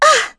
Yuria-Vox_Damage_02.wav